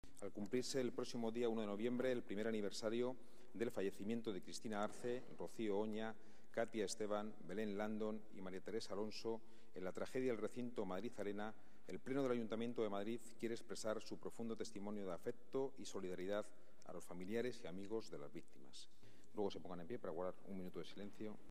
Nueva ventana:Declaraciones presidente del Pleno, Ángel Garrido. Declaración Institucional apoyo víctimas Madrid Arena.